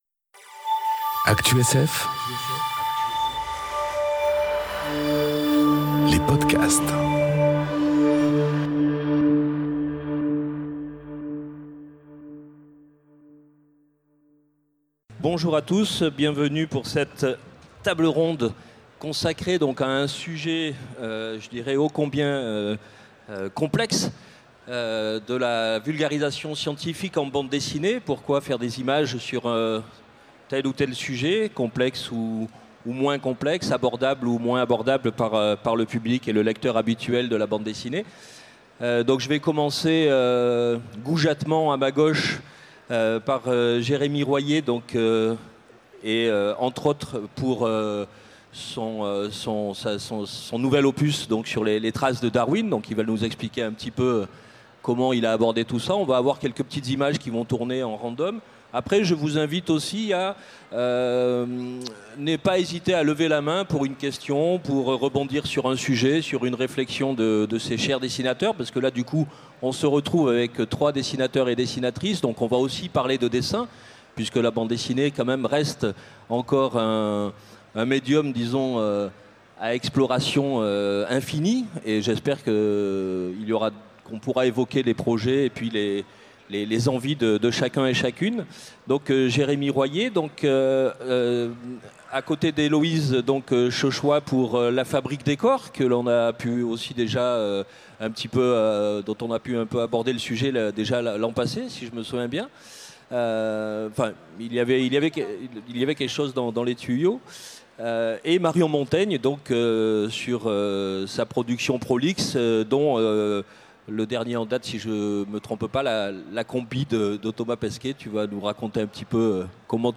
Conférence La vulgarisation scientifique dans la BD enregistrée aux Utopiales 2018